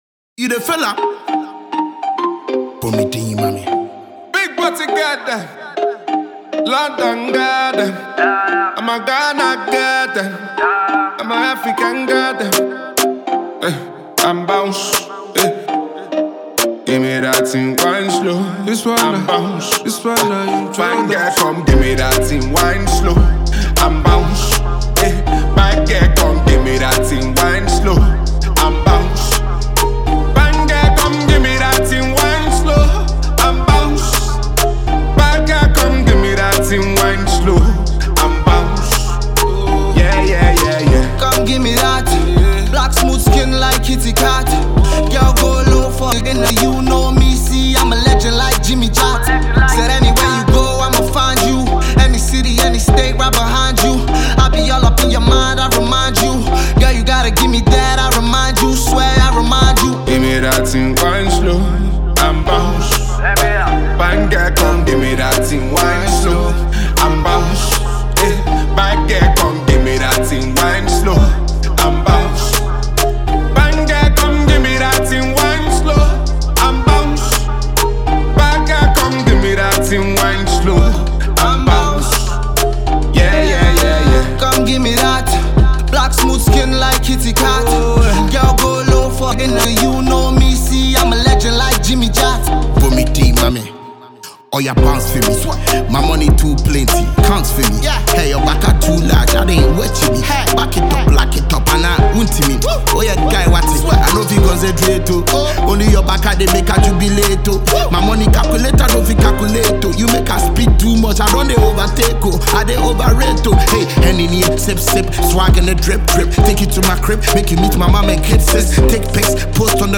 Ghana Music Listen Next Release